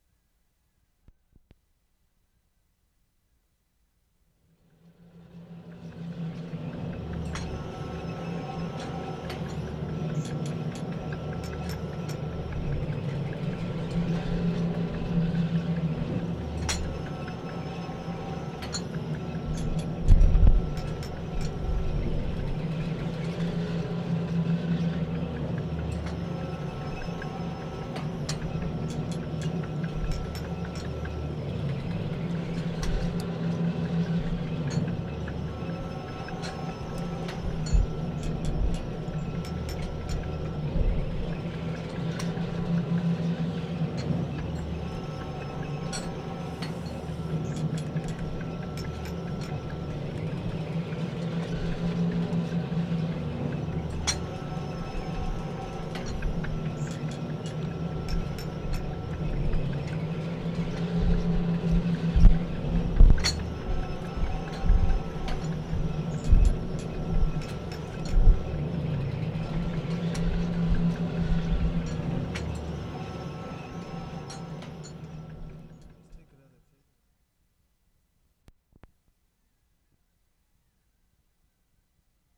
WORLD SOUNDSCAPE PROJECT TAPE LIBRARY
ALLIANCE, ALBERTA Sept. 23, 1973
ANOTHER GOONEYBIRD, OIL PUMP 1'15"
2,3. Very different from above: complex internal rhythms in a general hum.